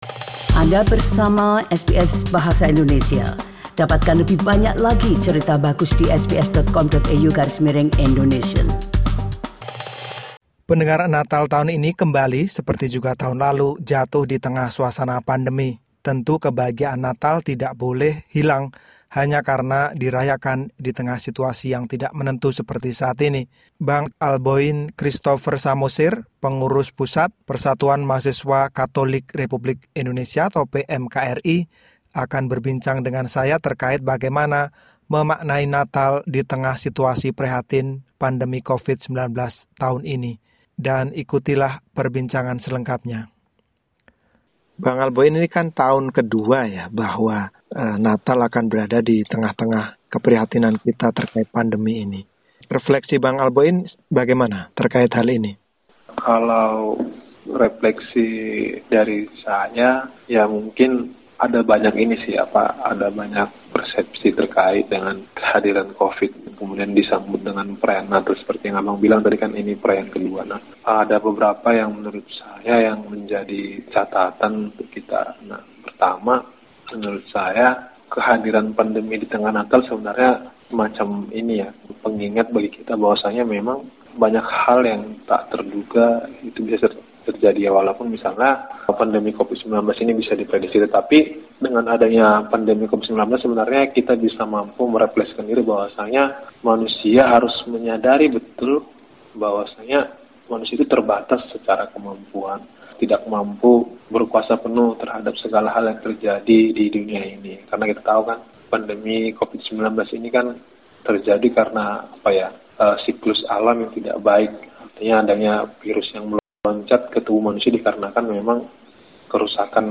berbagi perasaan dan kebahagiaan Natal dalam wawancara berikut.